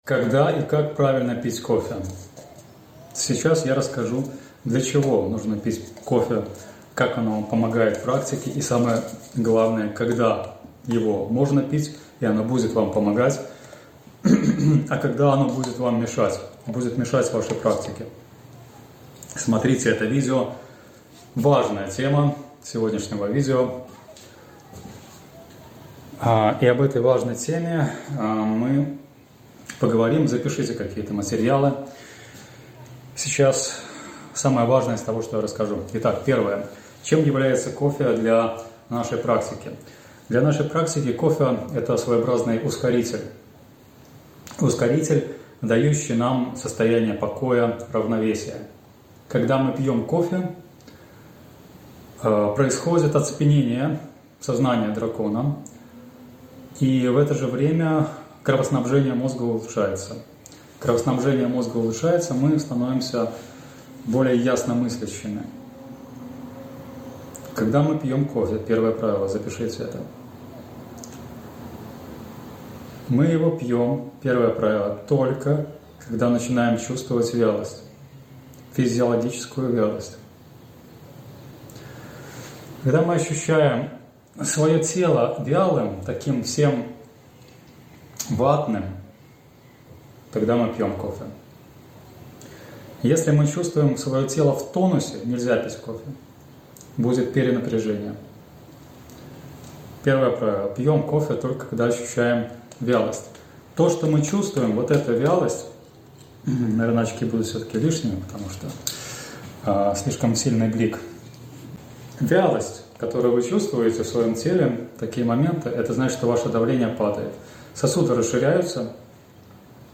# Голосовая заметка